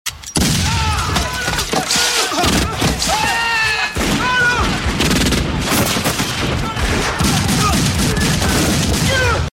REPETITIVE GUNFIRE AND SCREAMS.mp3
Original creative-commons licensed sounds for DJ's and music producers, recorded with high quality studio microphones.
repetitive_gunfire_and_screams_kc6.ogg